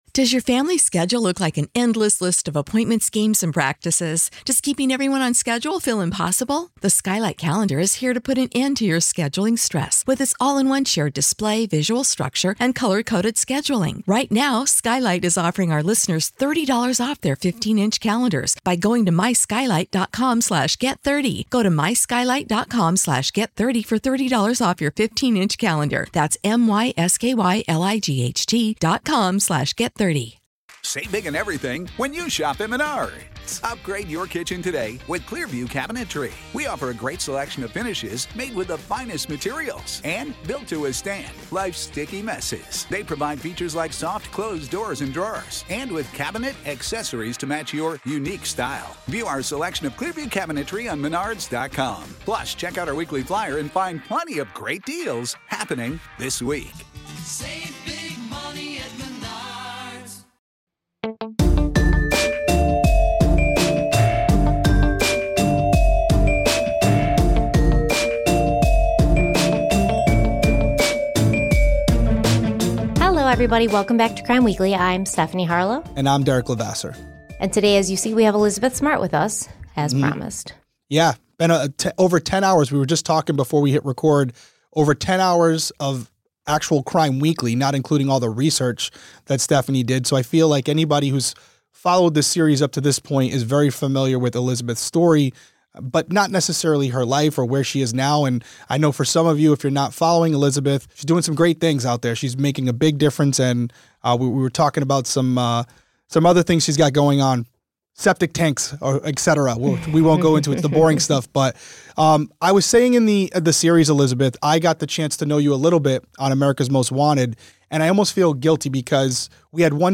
Our Interview With Elizabeth Smart